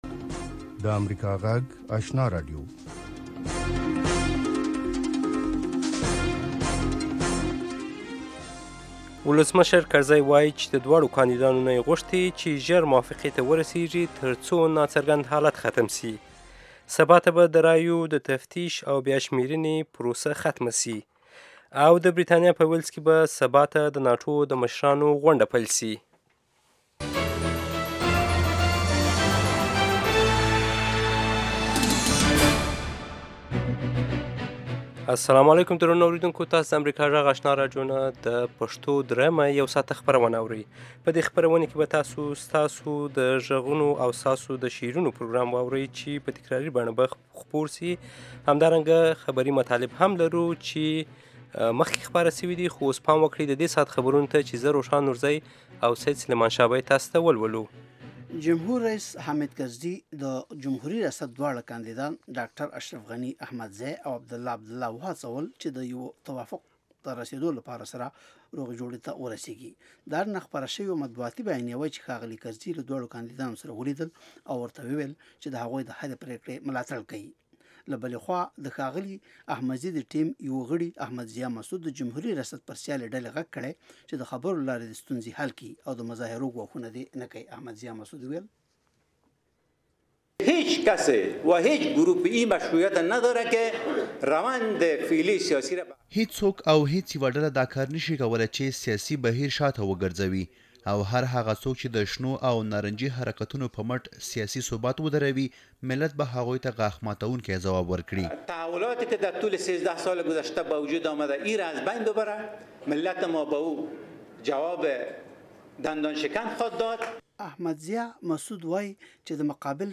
یو ساعته پروگرام: تازه خبرونه، او د نن شپې تېر شوي پروگرامونه په ثبت شوي بڼه، هنري، علمي او ادبي مسایلو په اړه د شعر، ادب او بیلا بیلو هنرونو له وتلو څیرو سره.